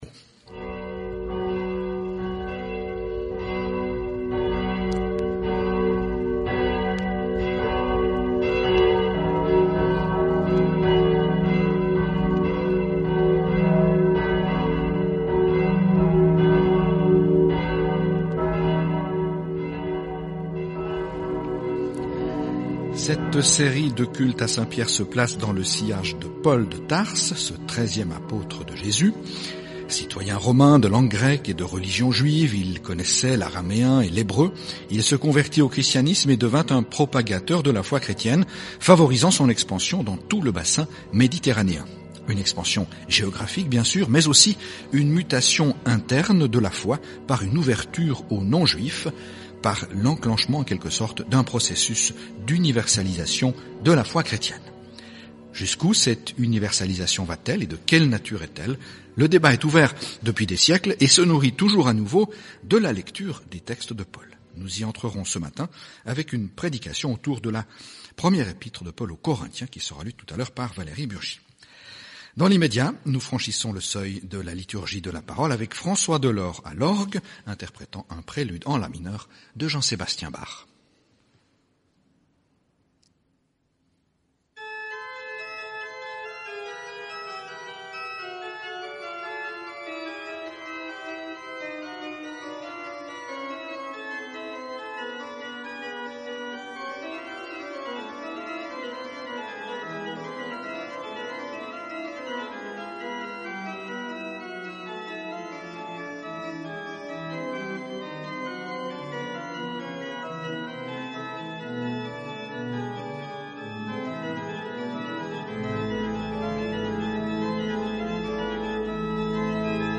Prédications dans le même lieu